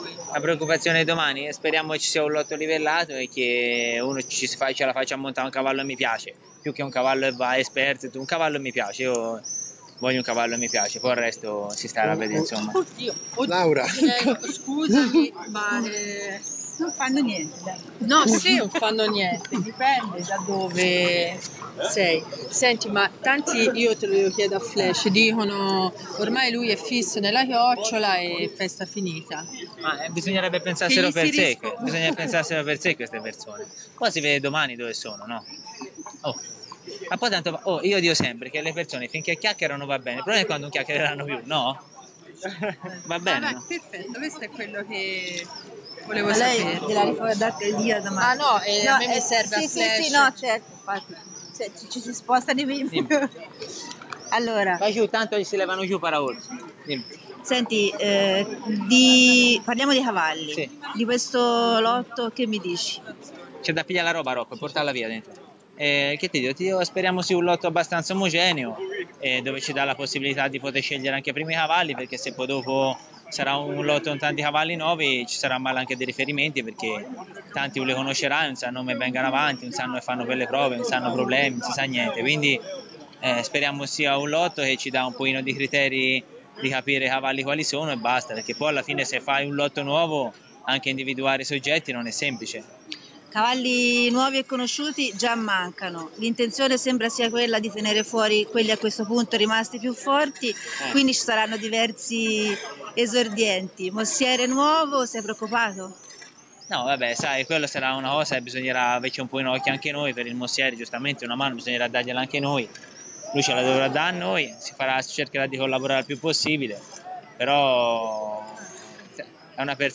Prove Regolamentate, le interviste: